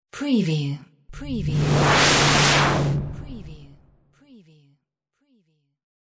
Transition whoosh 25
Stereo sound effect - Wav.16 bit/44.1 KHz and Mp3 128 Kbps
previewTLFE_DISTORTED_TR_WBHD25.mp3